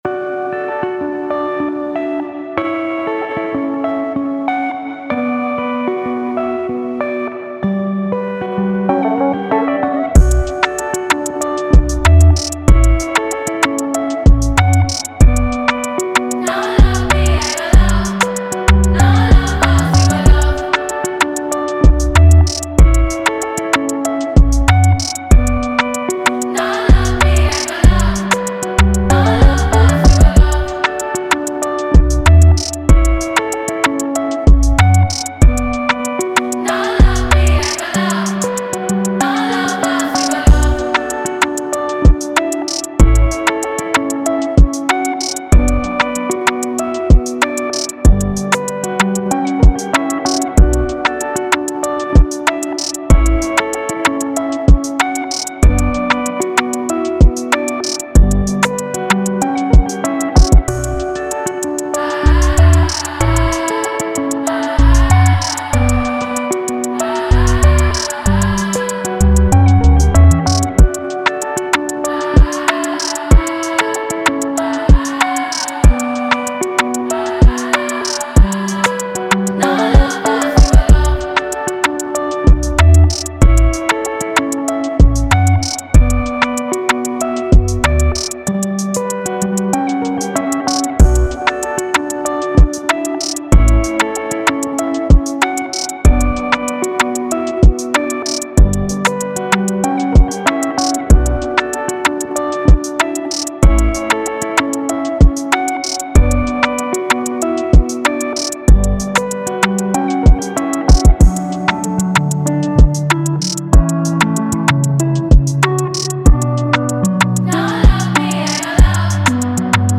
official instrumental
2025 in Dancehall/Afrobeats Instrumentals